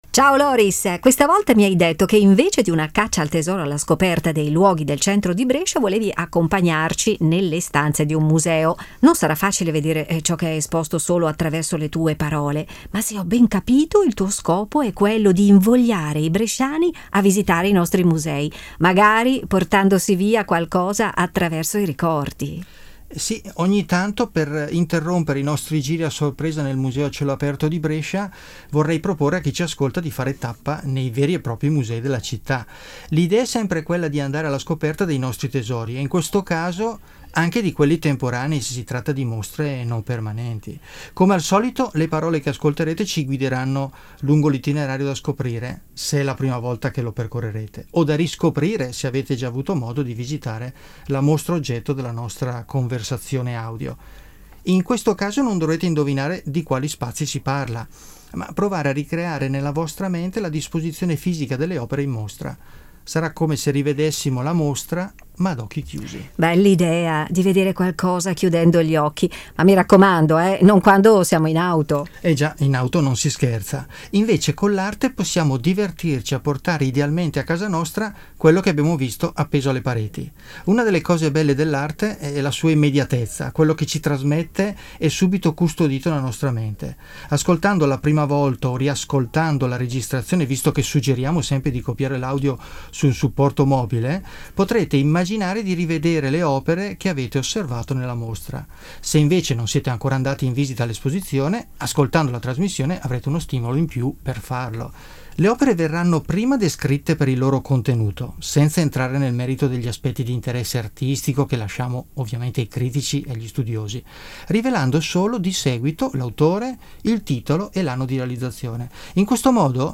audio-guida e itinerari per passeggiare tra i musei